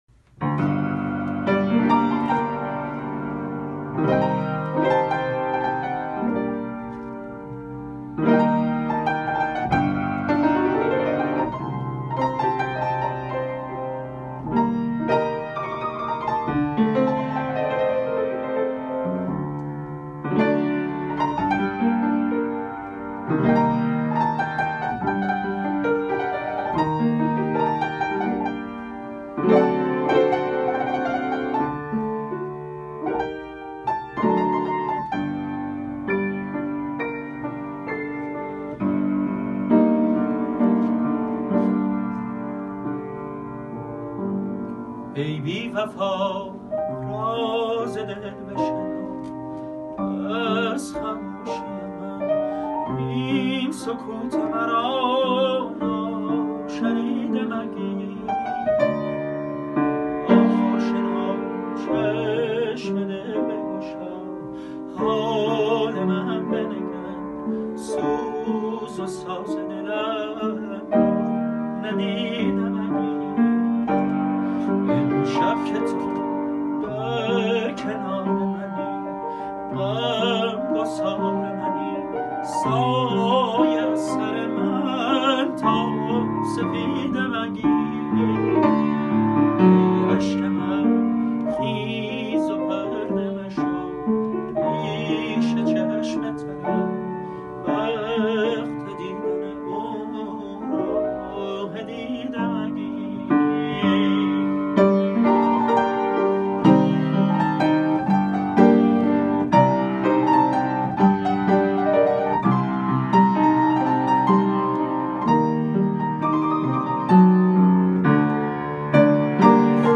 در دستگاه همایون با مایه‌ی آواز بیات و اصفهان